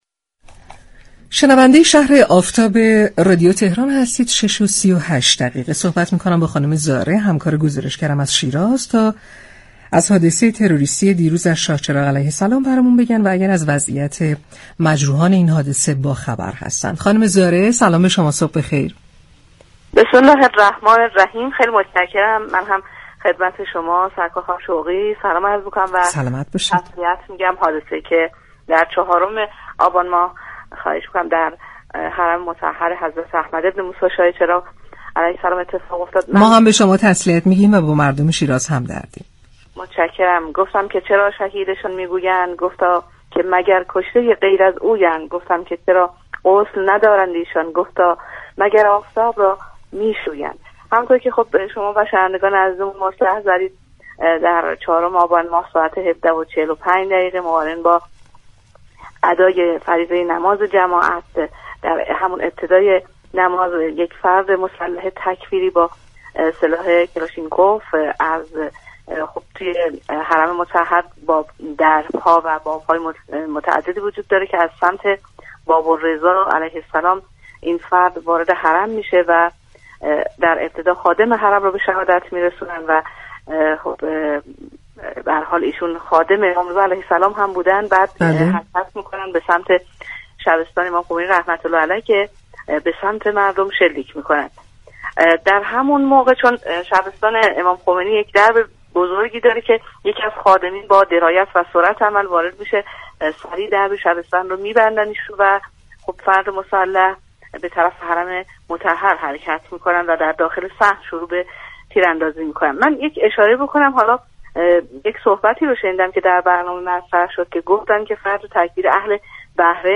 برنامه « شهرآفتاب» كاری از گروه برنامه ریزی و مدیریت شهری است و از شنبه تا پنجشنبه از ساعت 6:00 الی 8:15 از رادیو تهران پخش می شود.